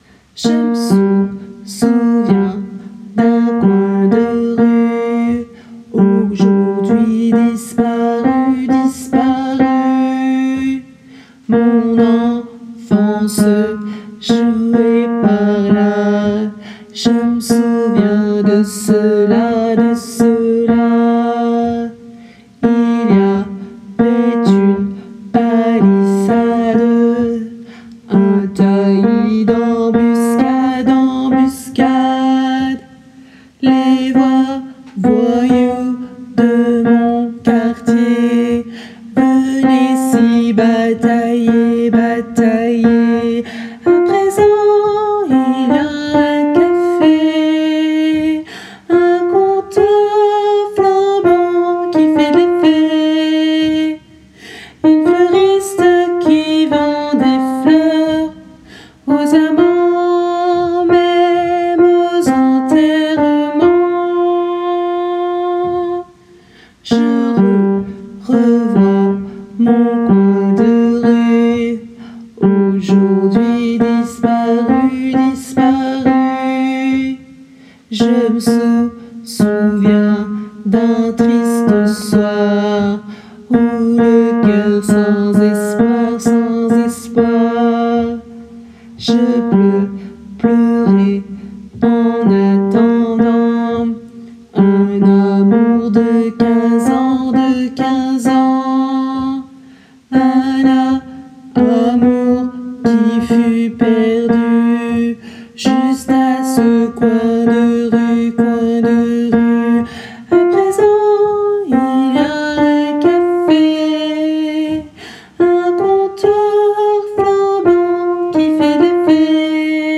Mp3 versions chantées
Soprano